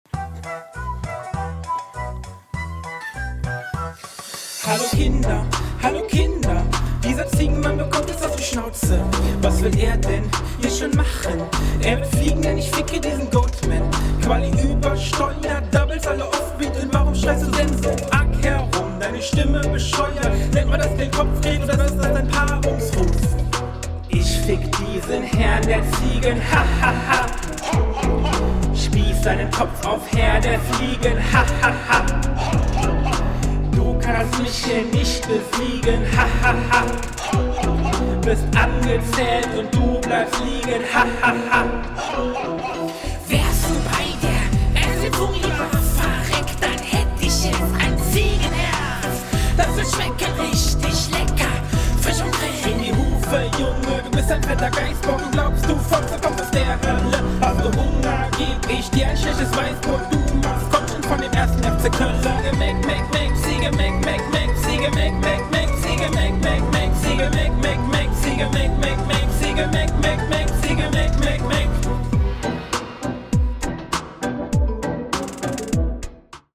So auf Kinderserien-Intro-Sound.